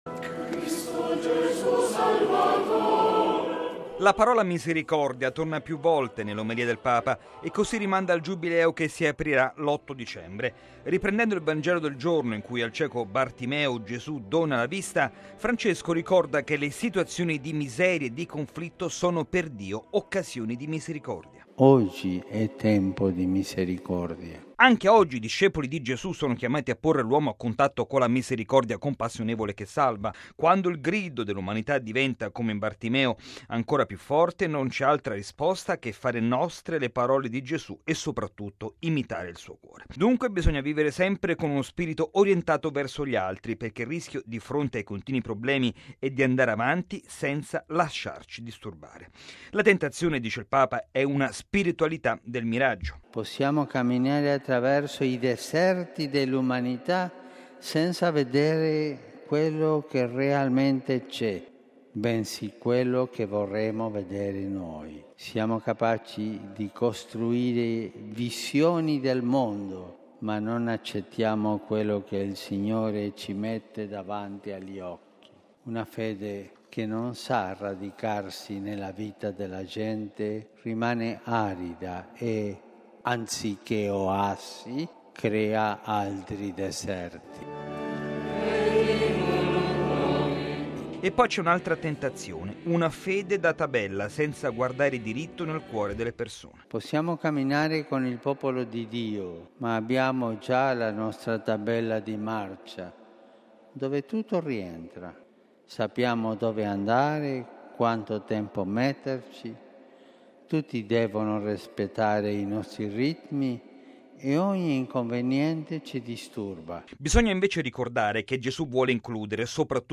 Bollettino Radiogiornale del 25/10/2015